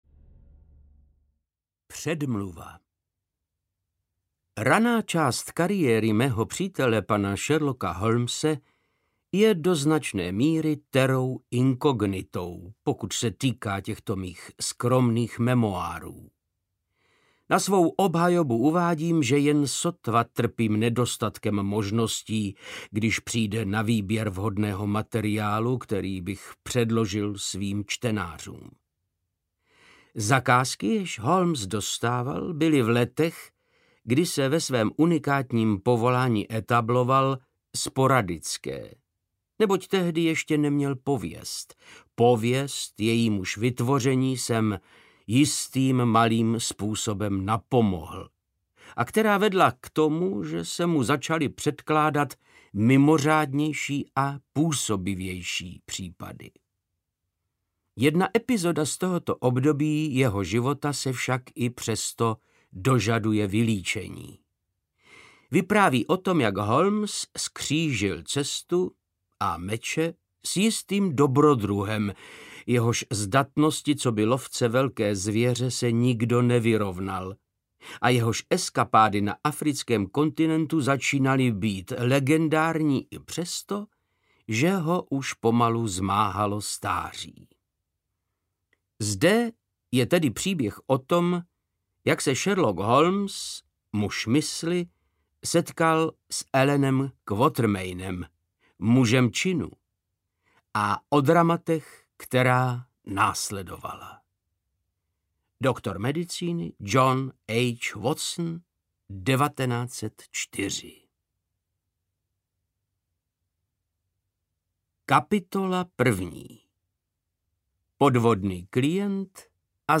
Sherlock Holmes a Ďáblův prach audiokniha
Ukázka z knihy
• InterpretVáclav Knop